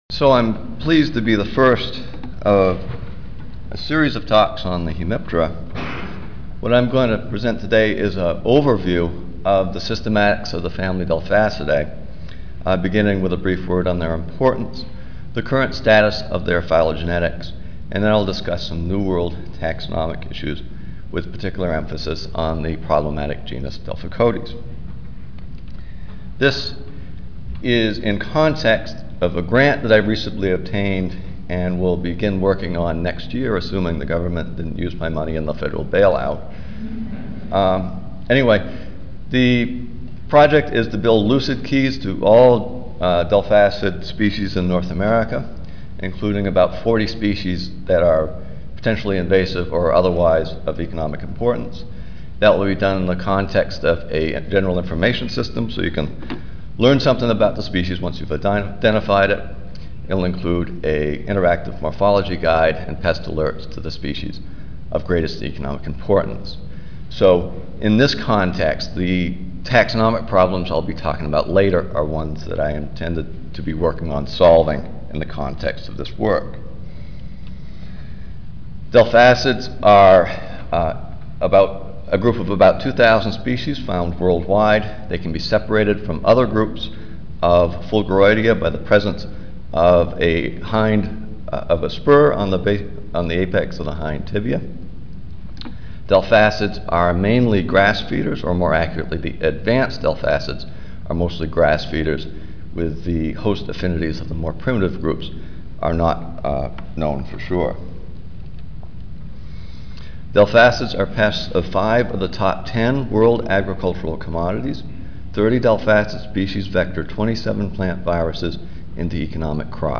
Room A5, First Floor (Reno-Sparks Convention Center)
Ten Minute Paper (TMP) Oral